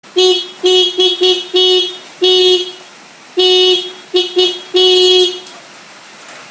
Tiếng Còi xe máy
Download tiếng còi xe máy mp3 chất lượng cao, tải hiệu ứng âm thanh tiếng bấm còi xe máy kêu tít tít mp3, tải tiếng nhấn còi xe máy giục giã chất lượng cao...